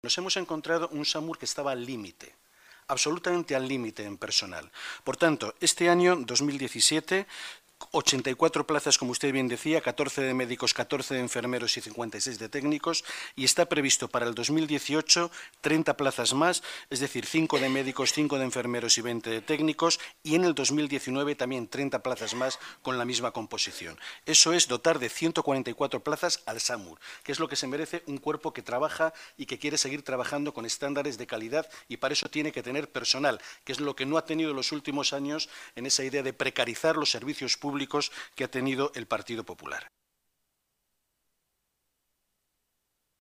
Barbero explica el Plan de Empleo y los procesos de promoción y selección en la Comisión Informativa del Área de Salud, Seguridad y Emergencias